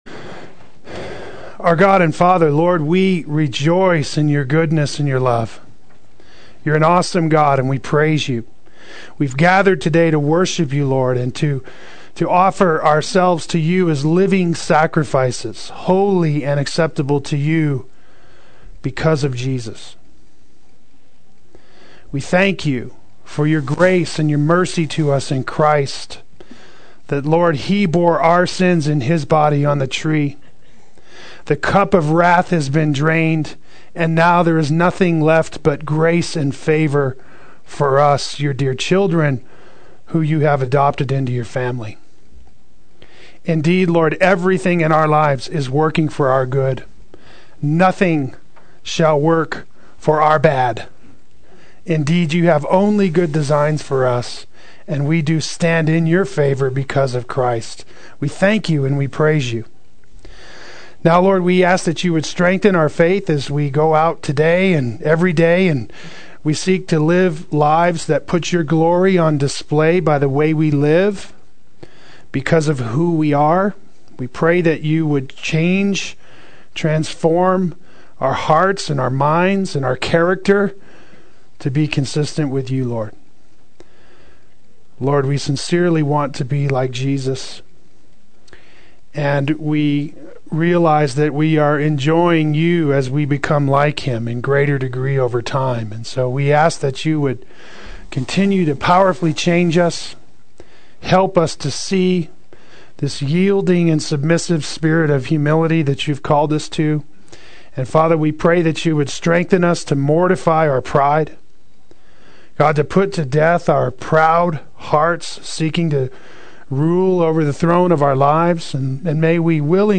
Spiritual War and Taking Thoughts Captive Adult Sunday School